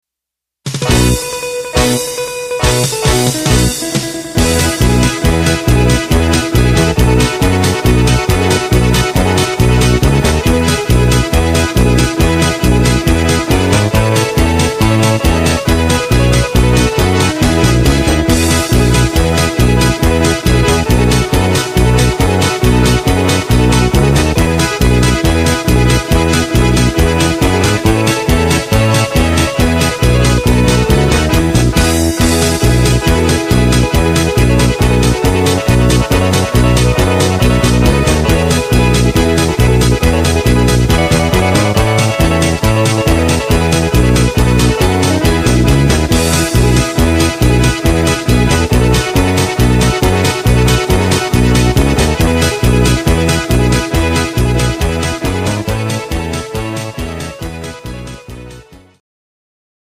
Rhythmus  Polka
Art  Instrumental Gitarre